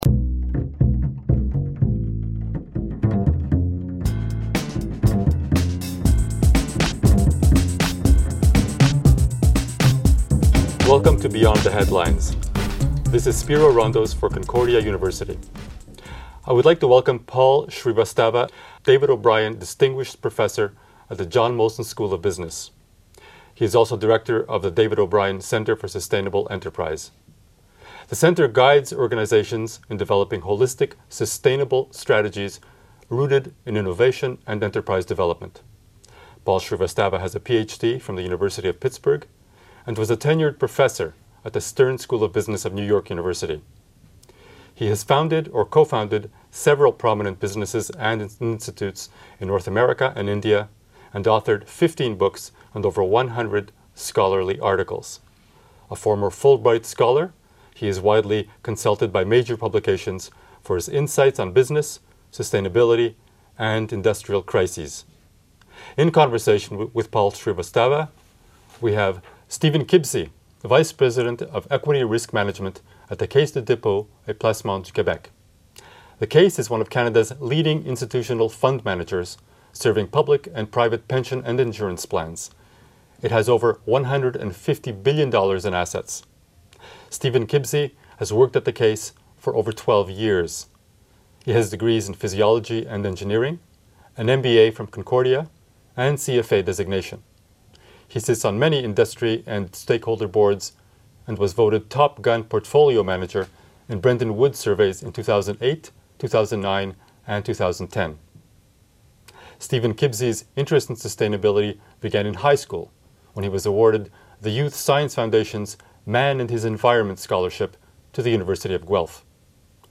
We have teamed them up with a community thought-leader to discuss a common topic, creating conversations intended to generate broader discussion and reflection.